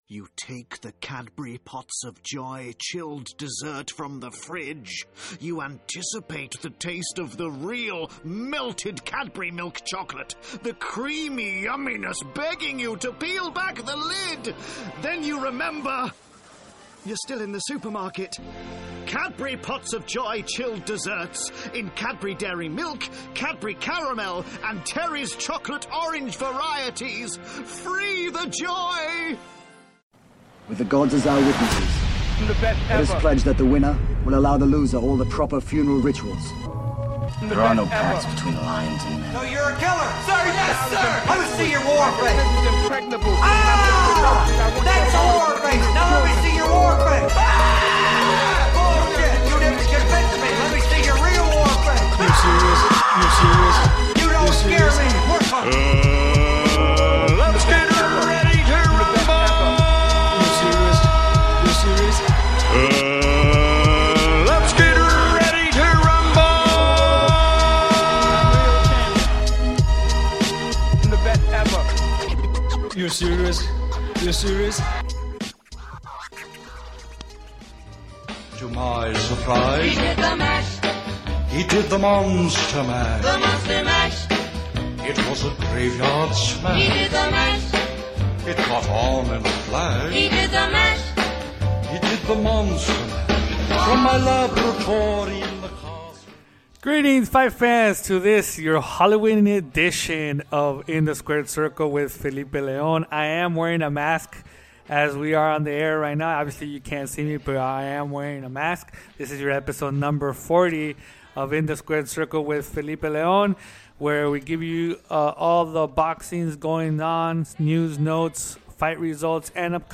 passes the latest news in the sport with a fast pace style of 30 minutes or less